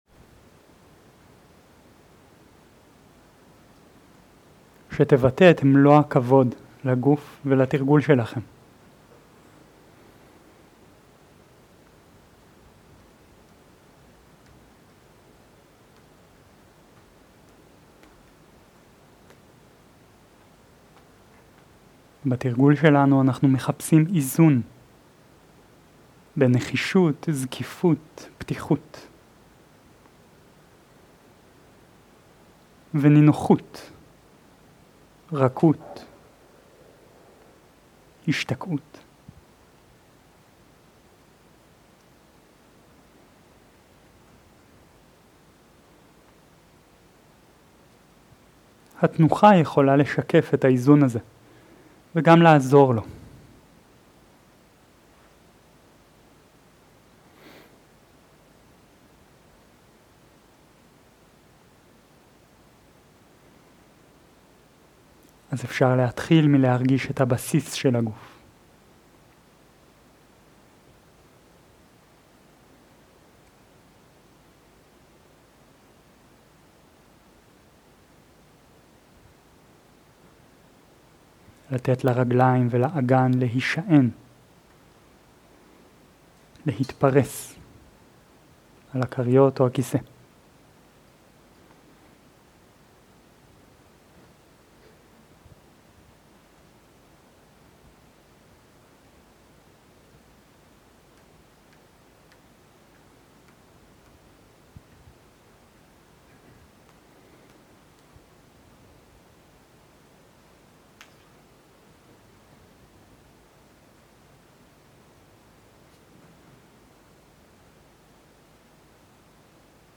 מדיטציה מונחית
סוג ההקלטה: מדיטציה מונחית
עברית איכות ההקלטה: איכות גבוהה מידע נוסף אודות ההקלטה
ריטריט חורף